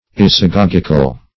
Search Result for " isagogical" : The Collaborative International Dictionary of English v.0.48: Isagogic \I"sa*gog"ic\, Isagogical \I"sa*gog"ic*al\, a. [L. isagogicus, Gr. ?.] Introductory; especially, introductory to the study of theology.